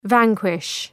Προφορά
{‘væŋkwıʃ}